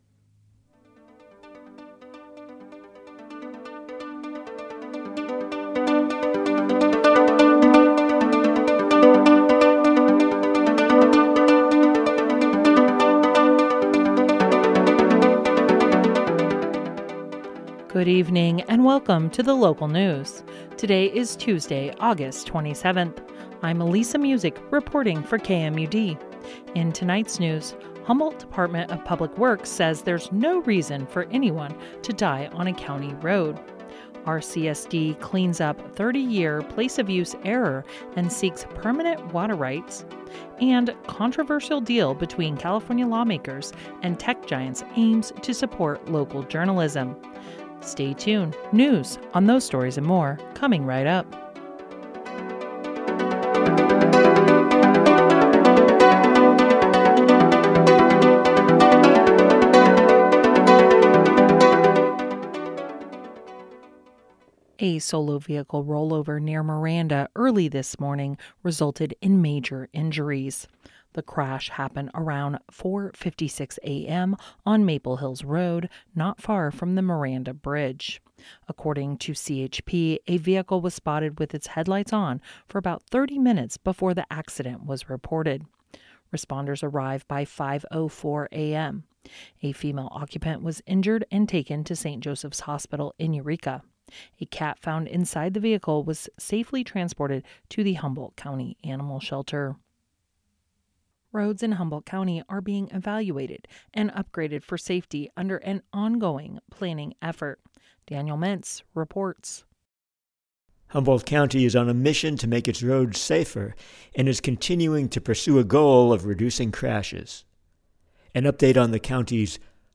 Local news.